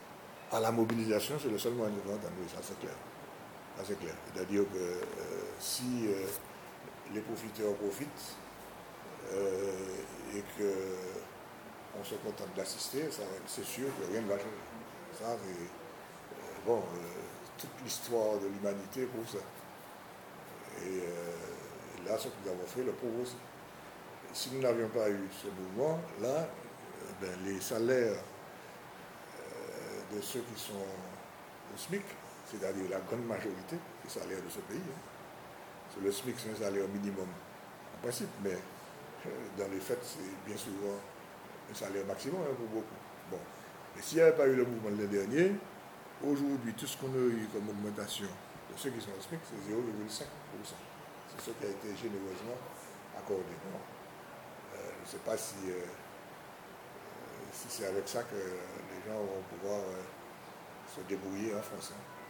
Interview à lire et à écouter.